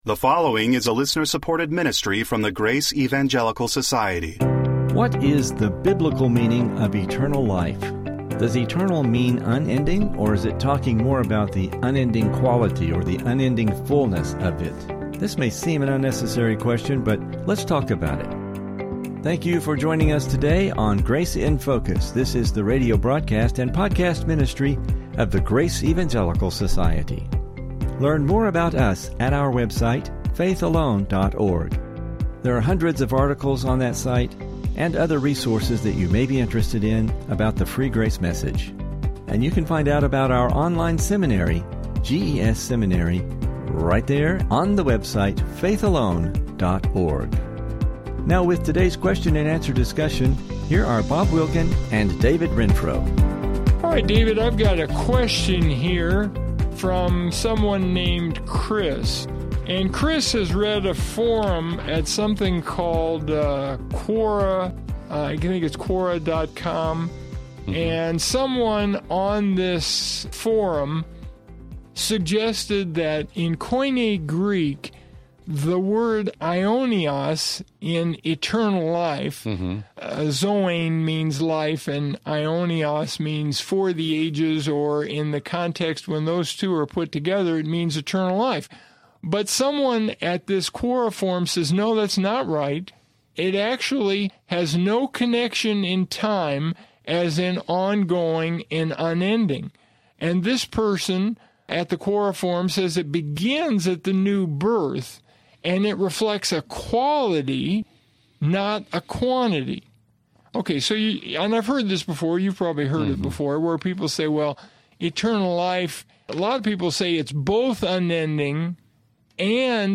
Welcome to the Grace in Focus radio.